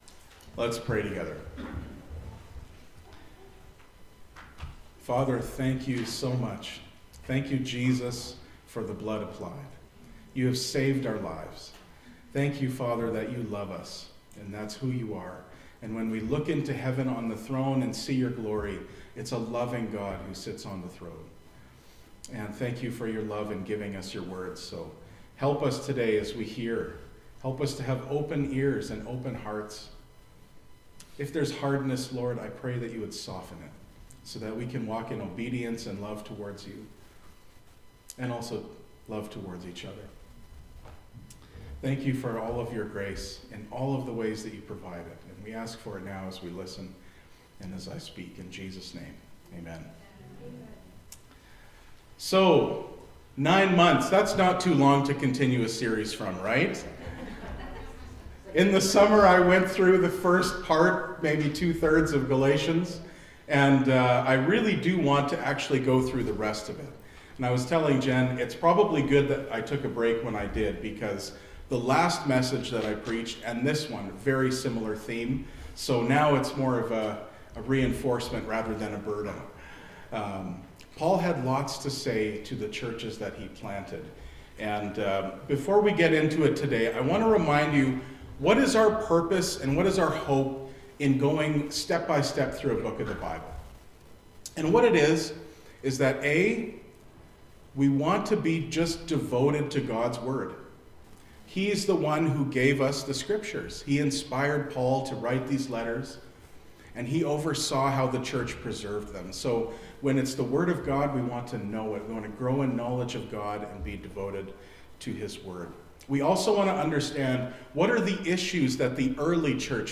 Galatians series: Galatians 4:12-5:12 | Faith Community Church: serving in the heart of Transcona!